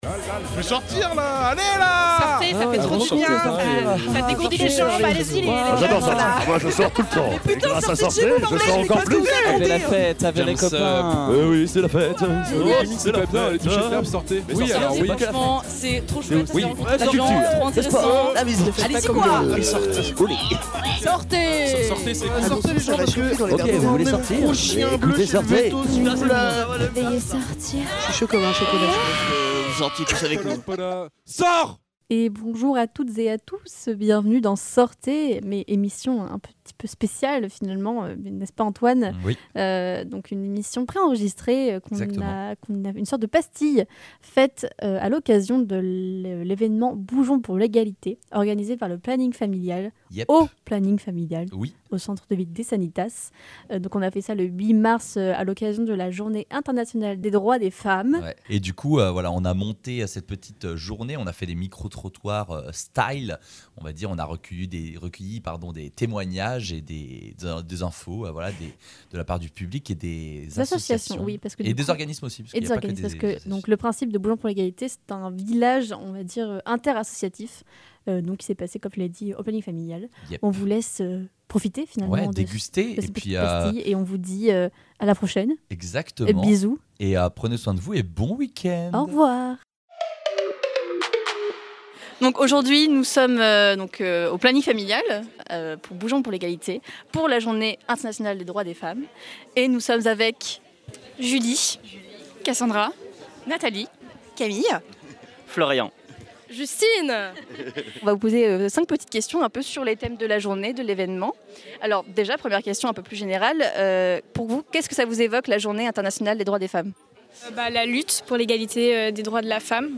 Matin : « Bougeons pour l’égalité » au Centre de Vie des Sanitas avec le Planning Familial 37
Durant cette journée, quelques questions concernant les différents sujets en lien avec la journée des droits des femmes ont été posées au public de ces deux évènements. En parallèle, plusieurs interviews ont été faites pour mettre en lumière le plus d’associations participantes possibles !